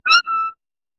さえずり